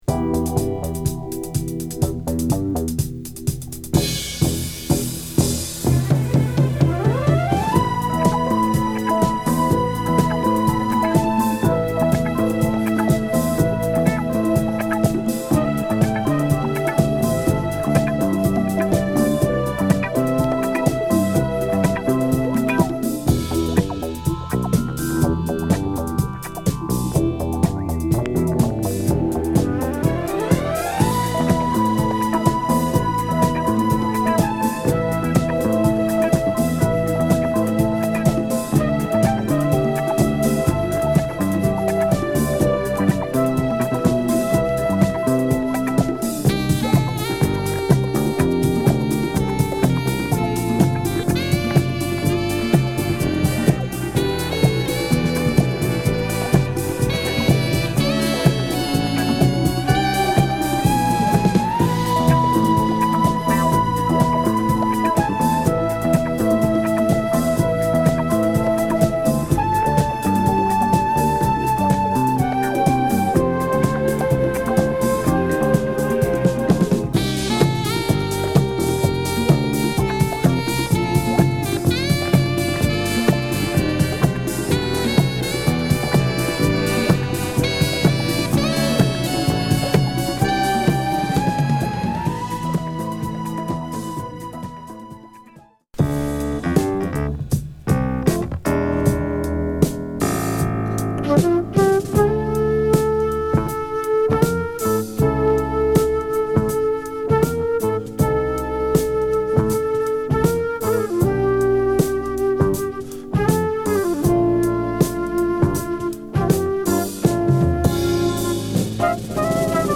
華やかなメロディラインも素晴らしい、ディスコ好きも要チェックな疾走感満点のダンス・チューン！